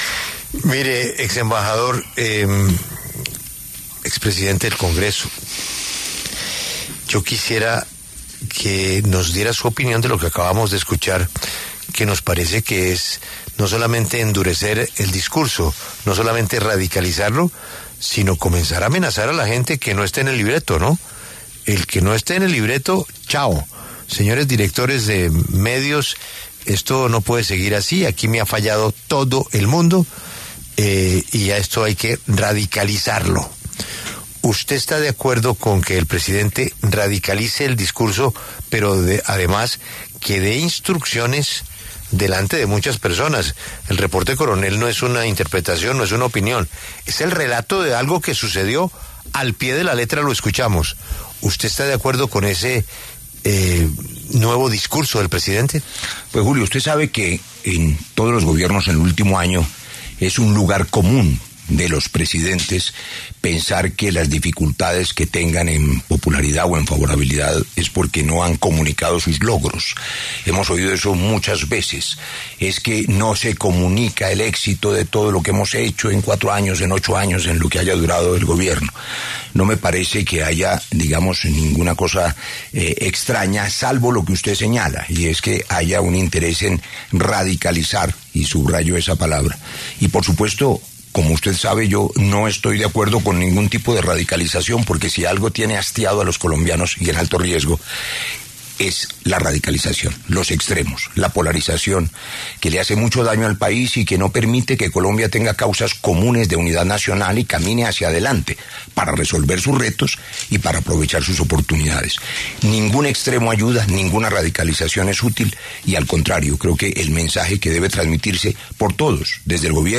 El exembajador Roy Barreras conversó con La W a propósito de la orden del presidente Gustavo Petro de “radicalizar el discurso” en sus últimos meses de Gobierno.